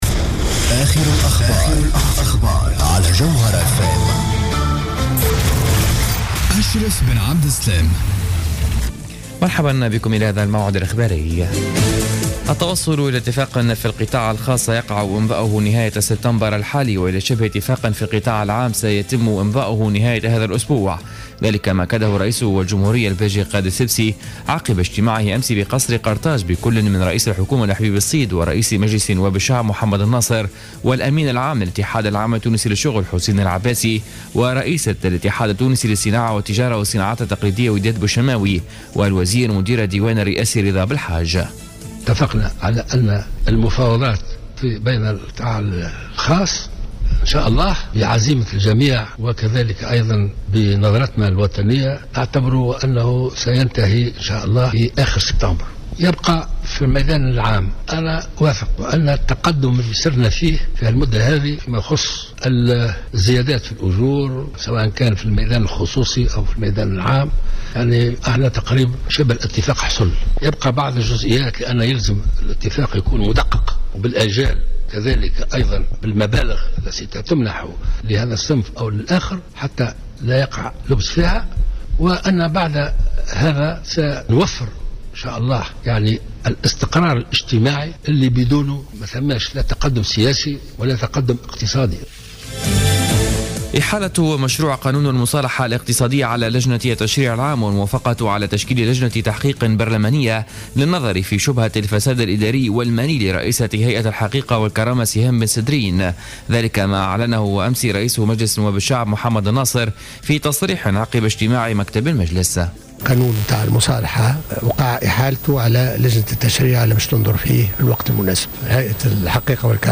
نشرة أخبار منتصف الليل ليوم الثلاثاء 8 سبتمبر 2015